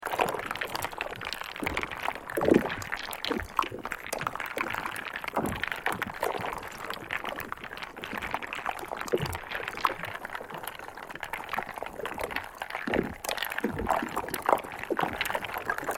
Звуки заморозки